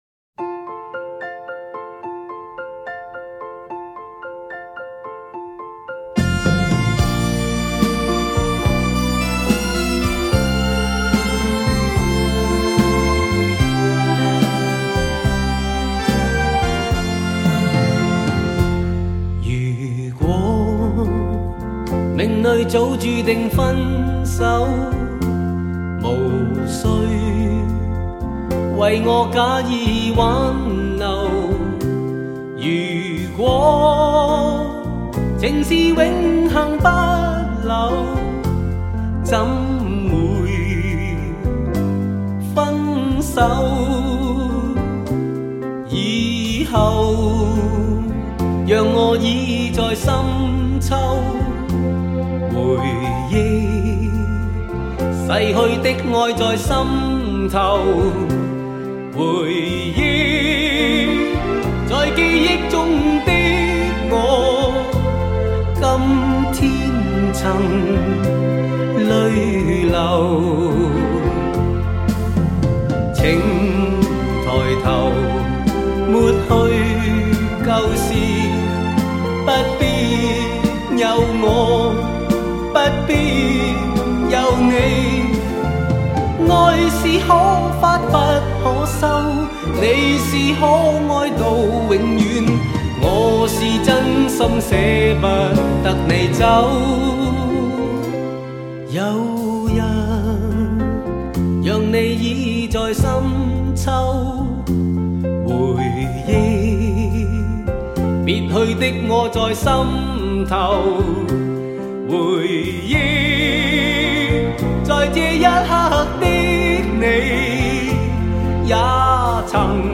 将100KHz宽频带/24bit音频信息载入
音色更接近模拟(Analogue)声效
强劲动态音效中横溢出细致韵味